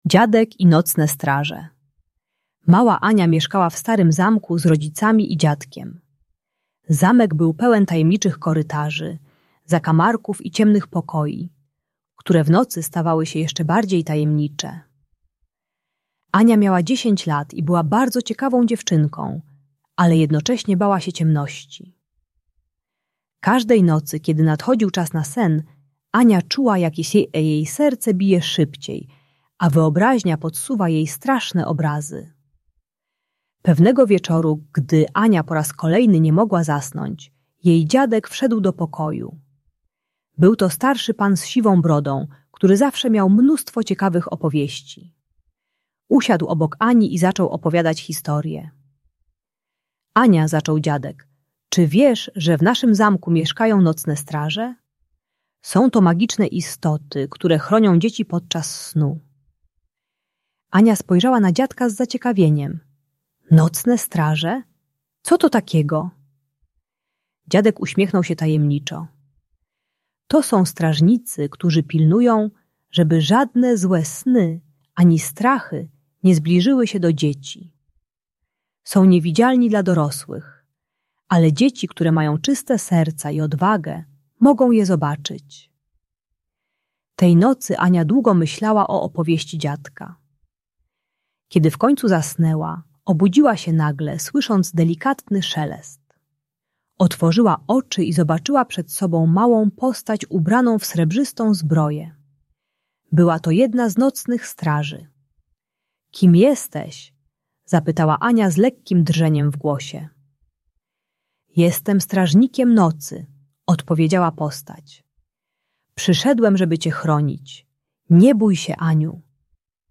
Ta audiobajka dla dzieci które boją się ciemności pomaga zrozumieć, że lęki są tylko w wyobraźni. Uczy techniki wizualizacji ochronnego strażnika i budowania wewnętrznej odwagi poprzez stopniowe mierzenie się ze strachem. Idealna bajka na dobranoc dla starszych przedszkolaków i młodszych uczniów.